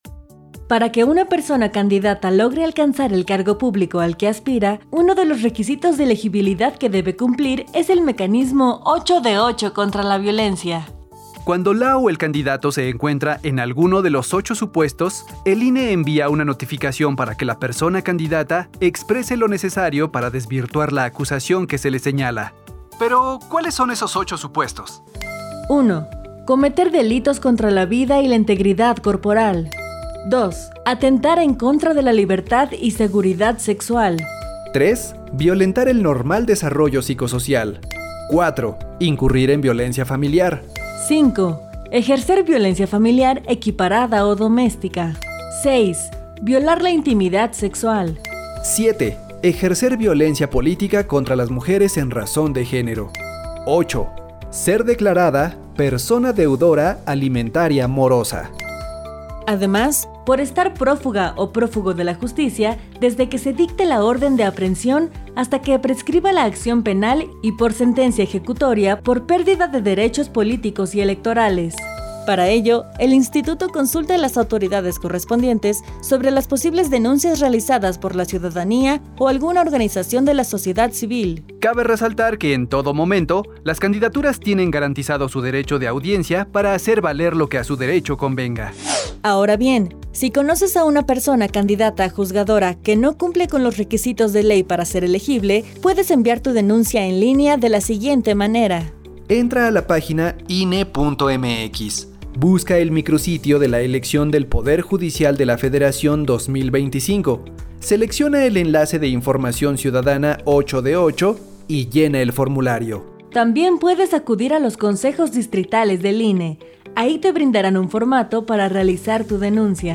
*Cápsula de audio sobre la violencia política contra las mujeres